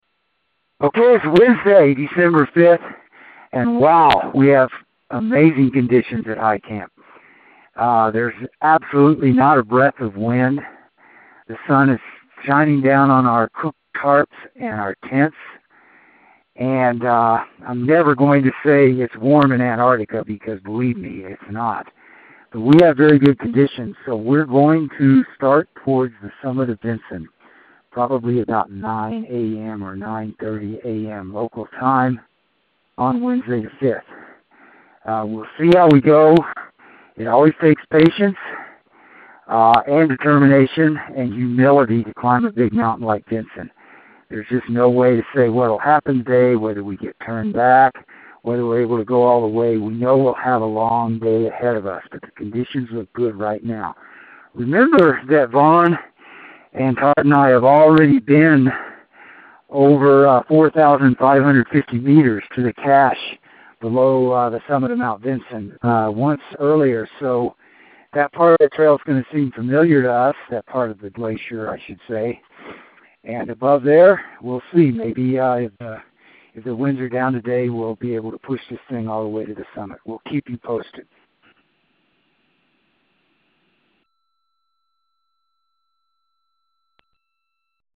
Preparing to Summit from High Camp